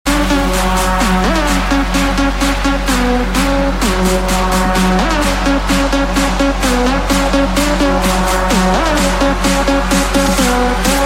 Jazz Ringtones